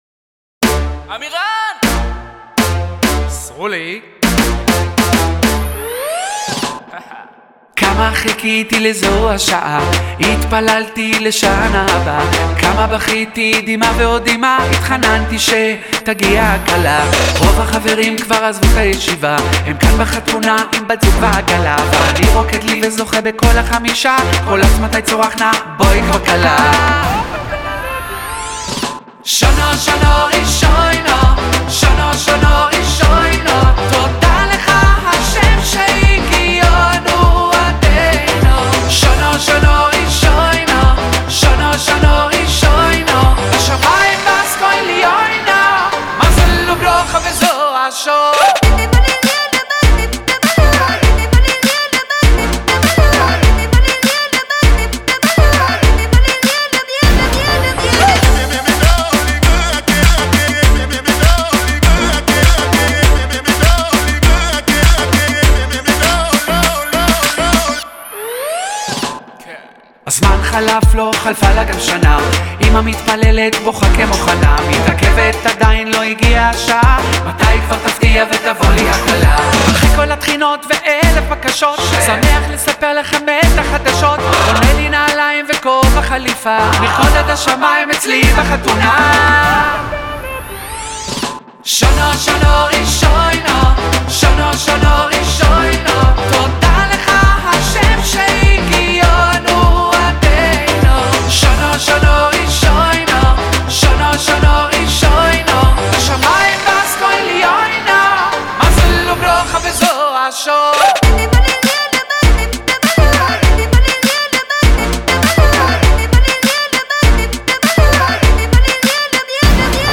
סינגל קצבי חדש